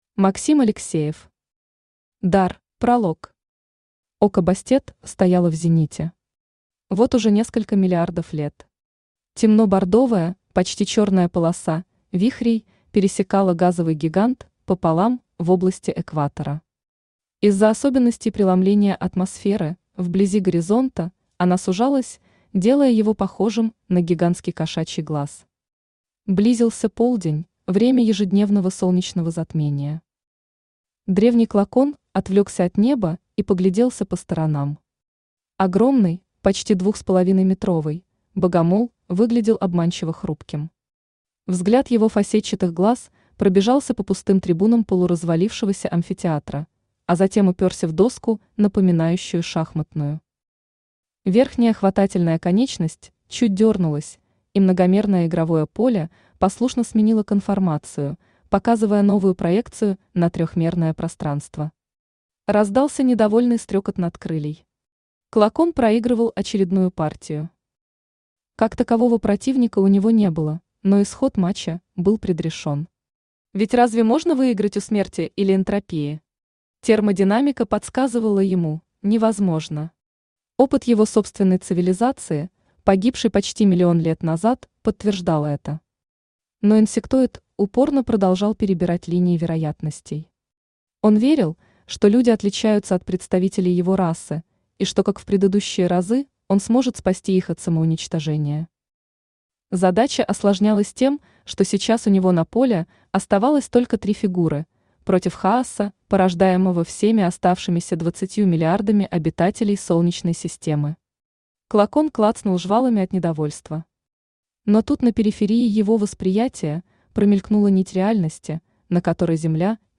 Аудиокнига Дар | Библиотека аудиокниг
Aудиокнига Дар Автор Максим Сергеевич Алексеев Читает аудиокнигу Авточтец ЛитРес.